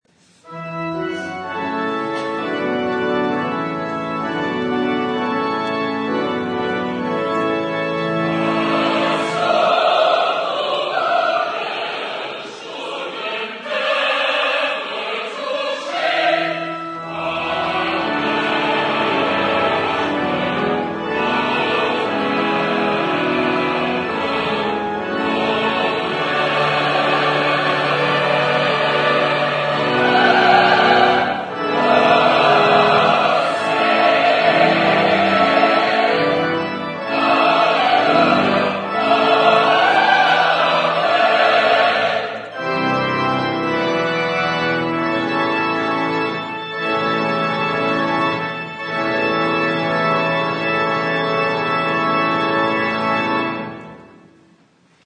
2008 media | Morningside Presbyterian Church